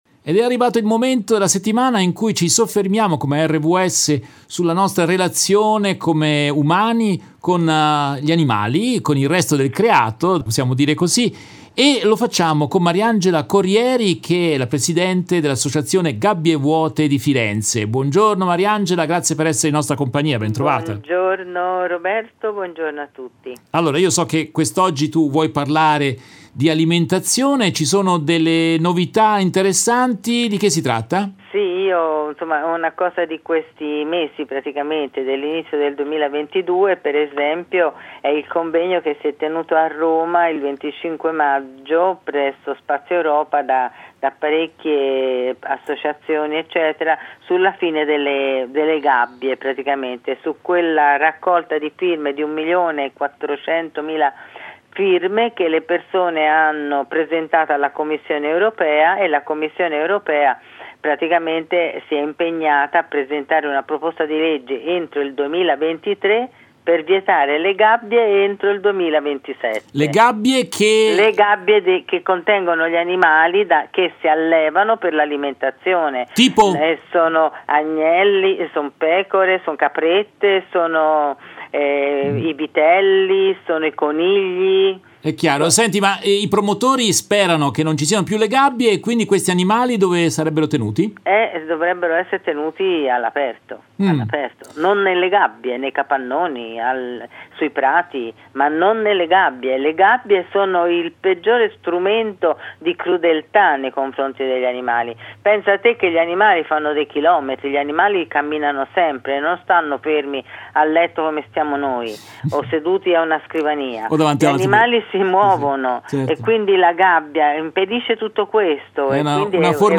In questa intervista tratta dalla diretta RVS del 3 giugno 2022